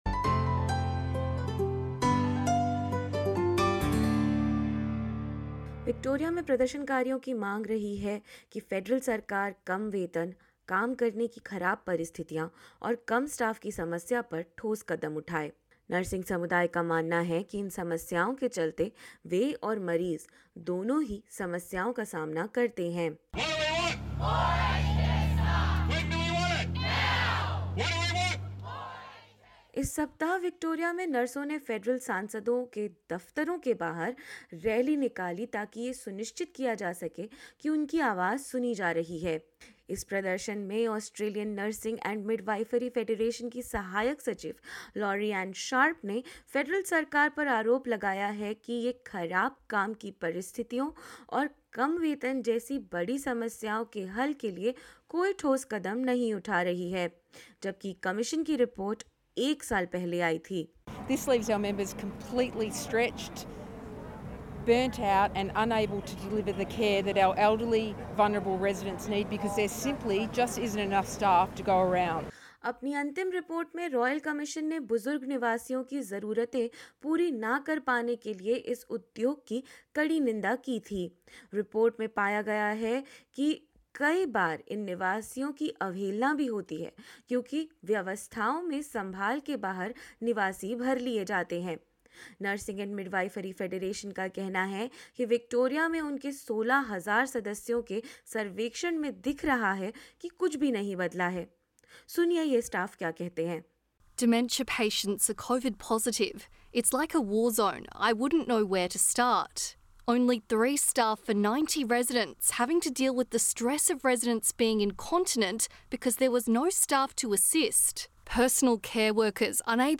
जानिए कि क्या कह रहा है नर्सिंग समुदाय और क्या रही है सरकार की प्रतिक्रिया, इस रिपोर्ट में।